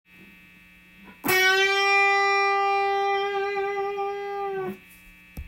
２弦７フレットをハーフチョーキング
ハーフチョーキングすることで半音隣のＧの音に変化してくれます。